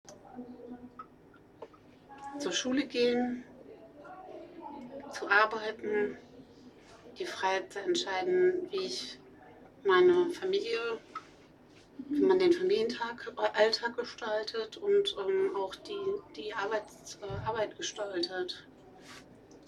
MS Wissenschaft @ Diverse Häfen
Standort war das Wechselnde Häfen in Deutschland.